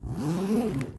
zip.ogg